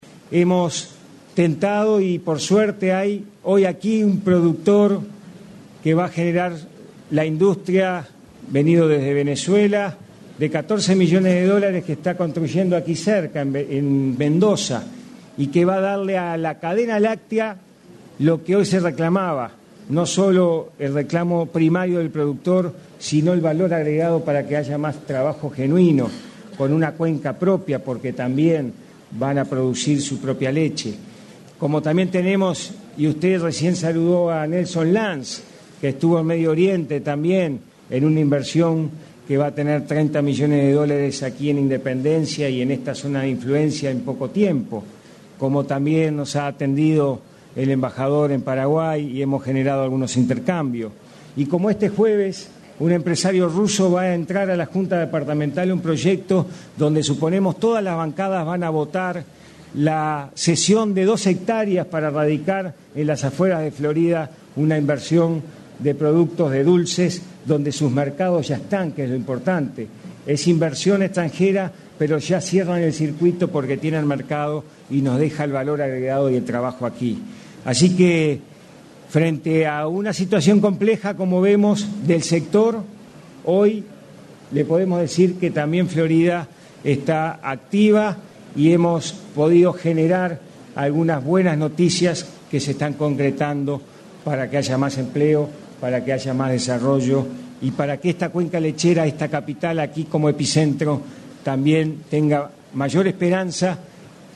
En el Consejo de Ministros abierto en Cardal, el intendente de Florida, Carlos Enciso, destacó las inversiones extranjeras que se están instalando en el departamento.